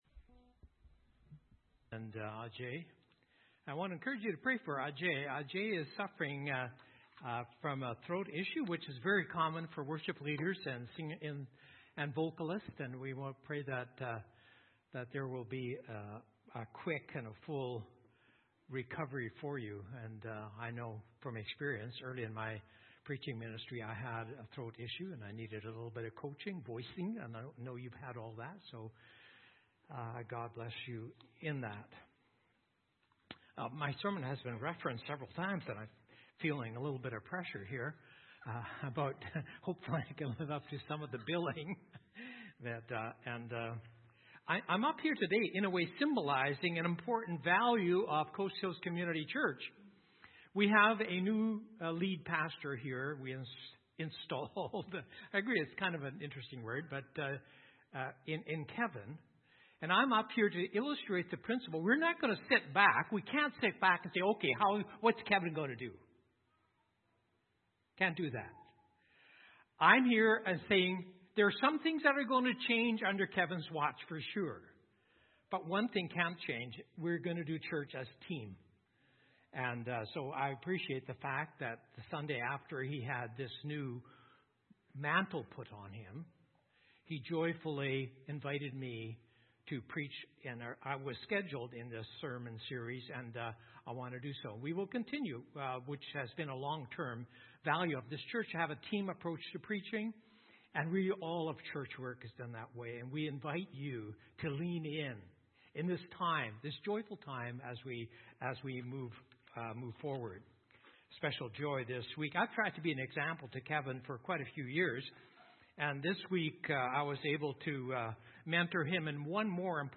Sermons | Coast Hills Community Church